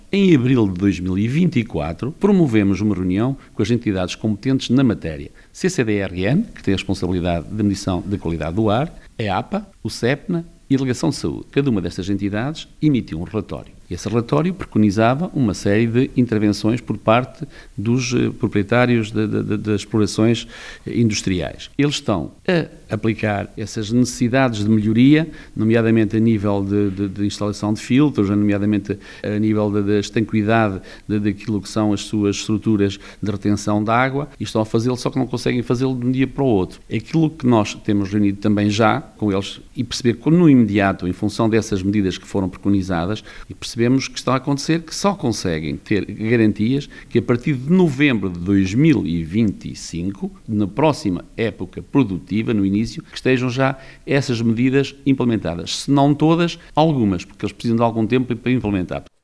Sobre os múltiplos relatos, o presidente do Município começa por referir que a preocupação do executivo sobre este assunto não é de agora: